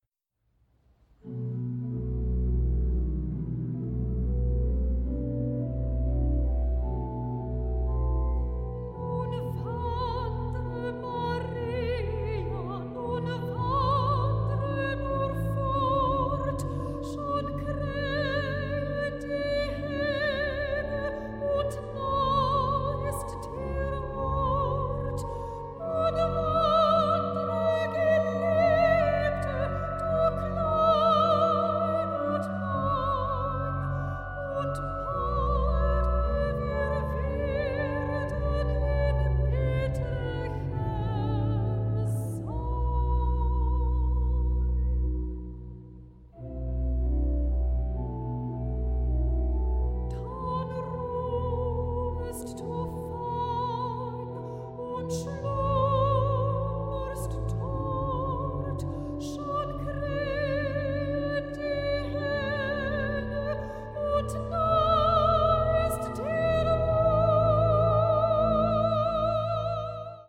arrangements for soprano and organ